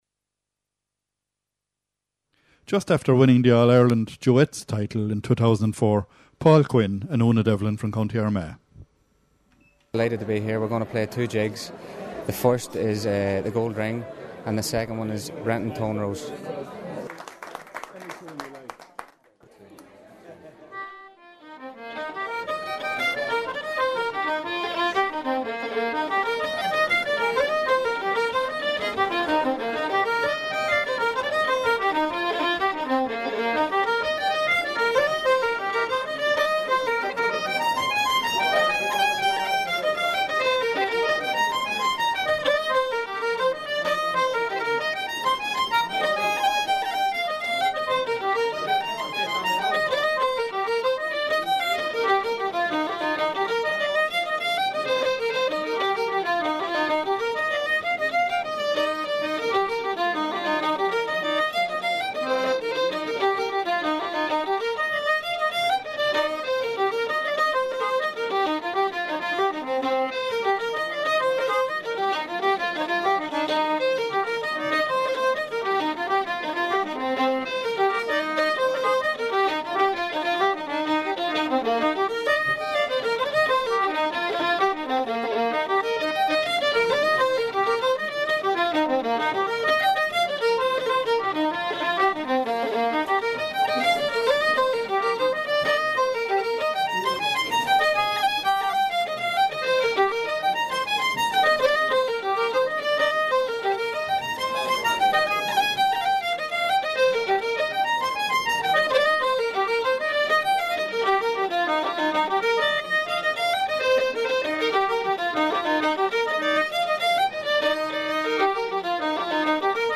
Co. Armagh celebrating their success celebrating their success shortly afterwards at a session with 2 jigs
Concertina
Fiddle
Fleadh, Session, Duet, Concertina, Fiddle, Jig, ComhaltasLive